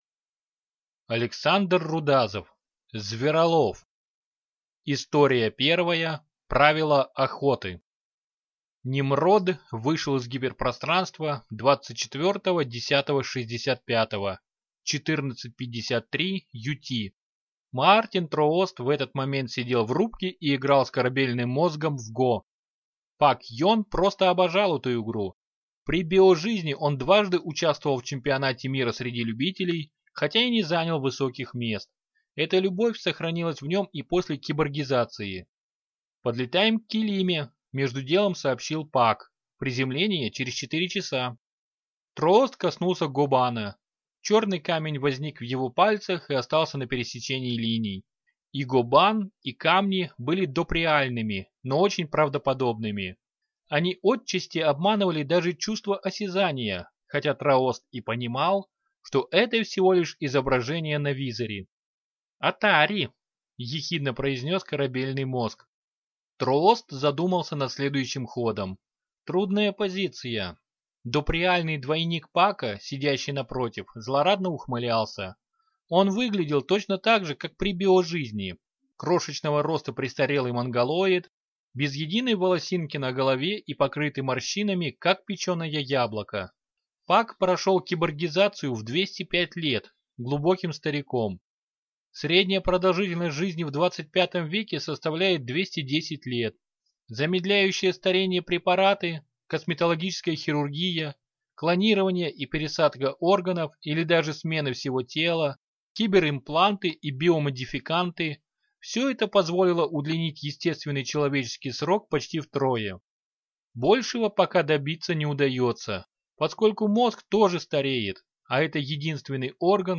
Аудиокнига Зверолов | Библиотека аудиокниг